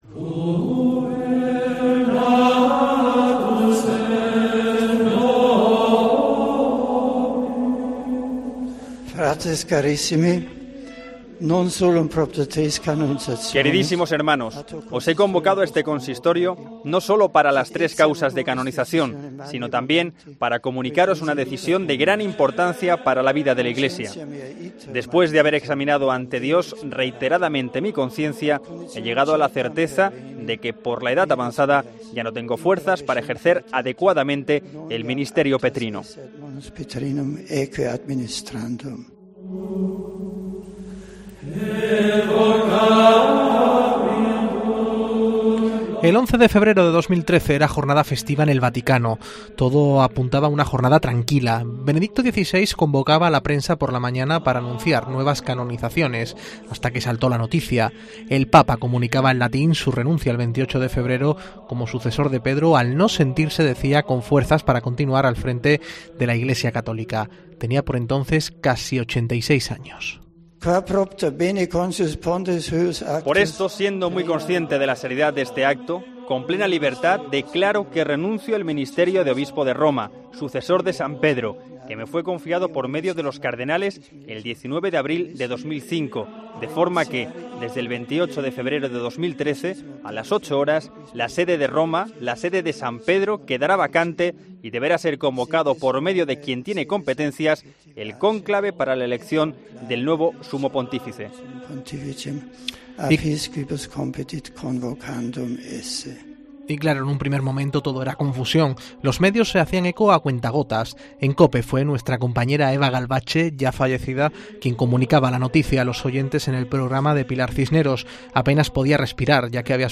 COPE pone en marcha una programación especial
Pero en cuestión de minutos la noticia fue confirmada y COPE puso en marcha rápidamente una programación especial para contar la última hora de esta histórica noticia y sus efectos en la Iglesia.